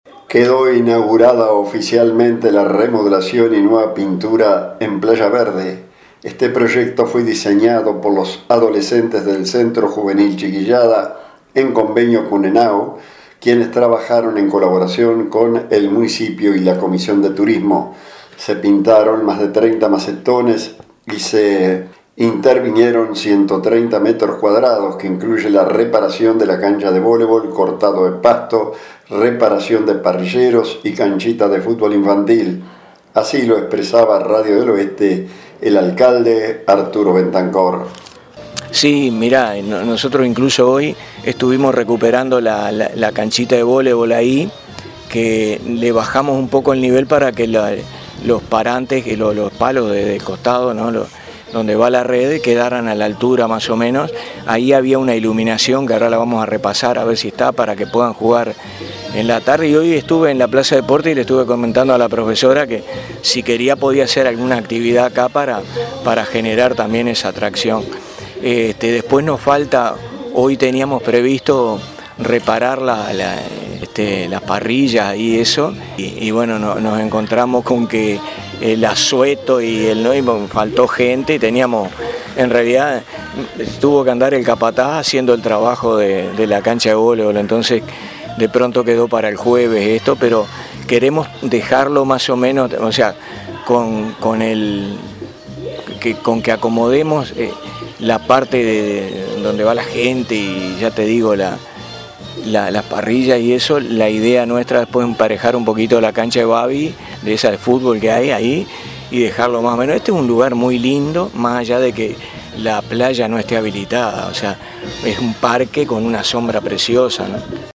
Se pintaron más de 30 macetones, se reparó la cancha de voleibol,cortado del pasto, reparación de los parrilleros y cancha de fútbol infantil, así lo manifestó a Radio del Oeste el alcalde de Juan Lacaze Arturo Bentancor.